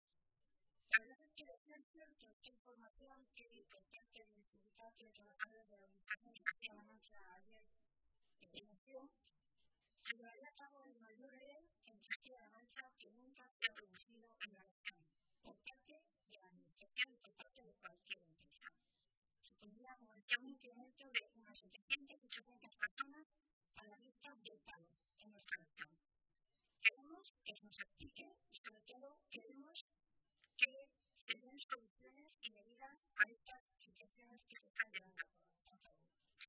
Milagros Tolón, portavoz de Empleo del Grupo Socialista
Cortes de audio de la rueda de prensa